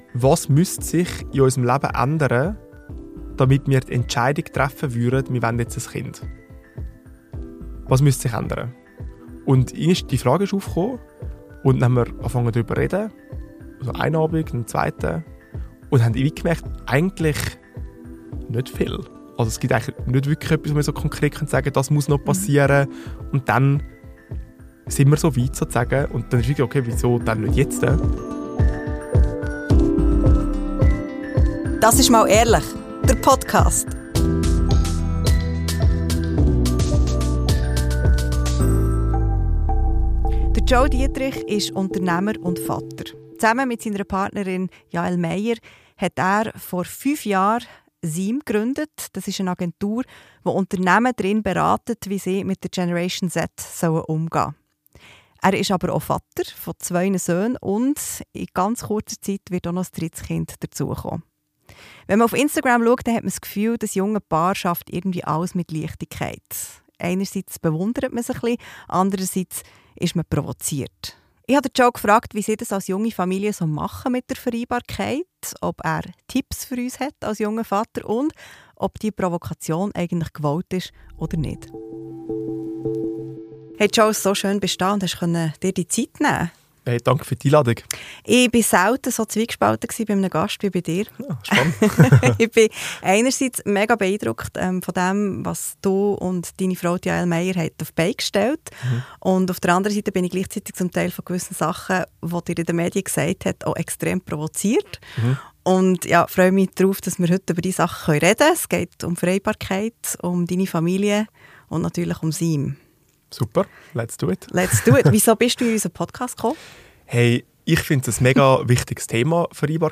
Gespräch über Unternehmertum, Familie und Vereinbarkeit